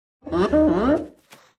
Minecraft Version Minecraft Version snapshot Latest Release | Latest Snapshot snapshot / assets / minecraft / sounds / mob / sniffer / happy1.ogg Compare With Compare With Latest Release | Latest Snapshot